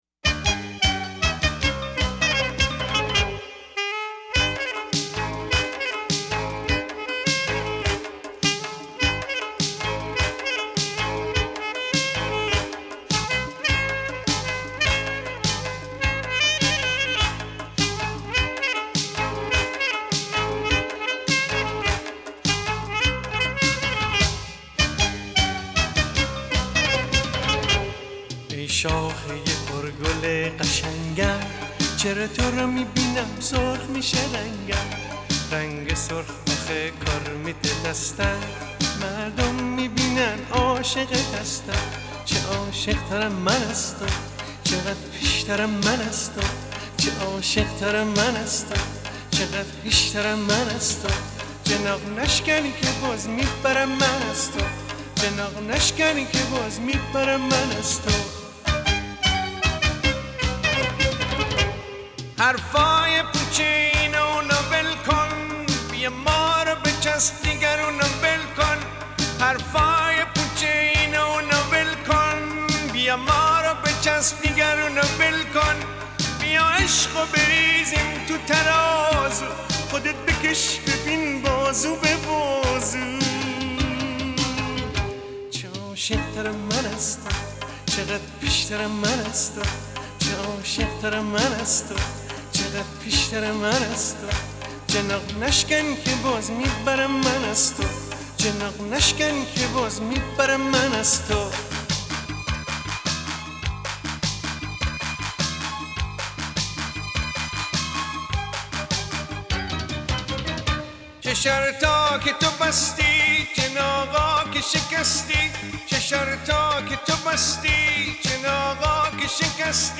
اهنگ پاپ ایرانی